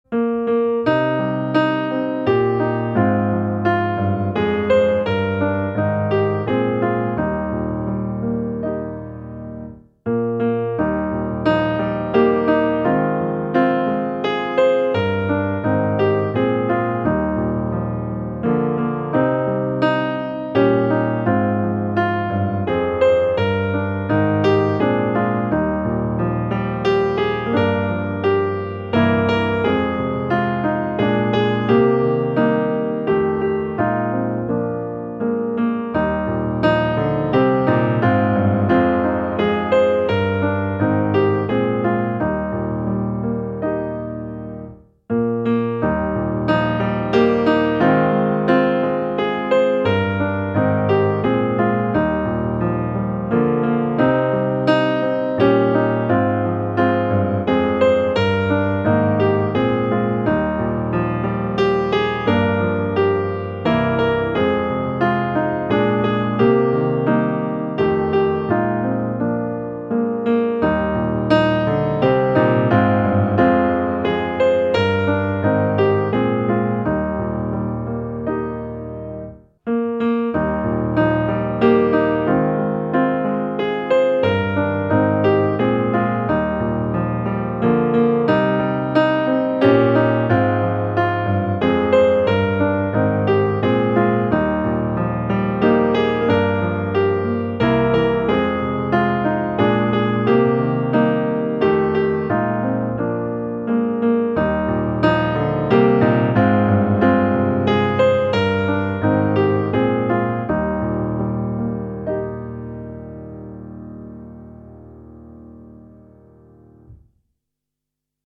Herre, se vi väntar alla - musikbakgrund